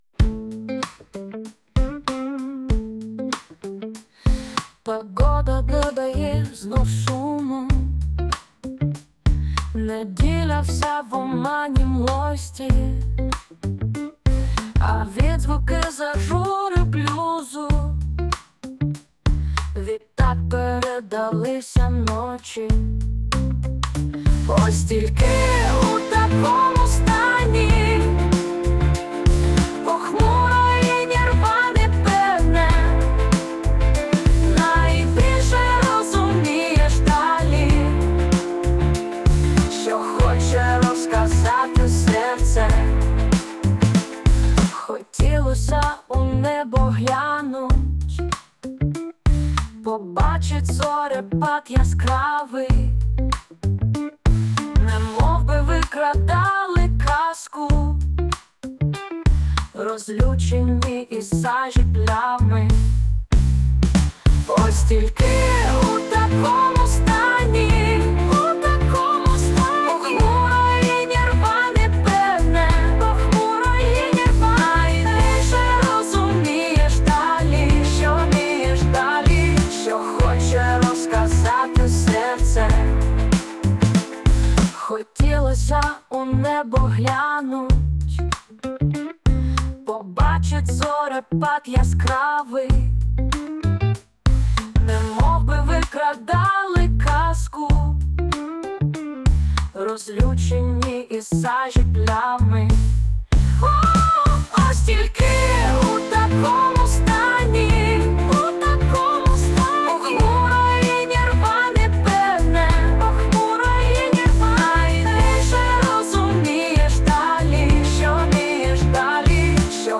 Музична композиція створена за допомогою SUNO AI
Сильний приспів і загалом крутезна пісня! hi